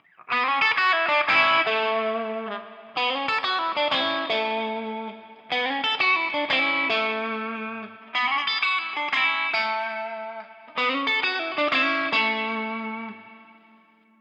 I've attached a recording here that moves through the positions from bridge to neck one at a time.
I don't know about y'all, but I've never heard an in-between position sound so comparatively weak and thin.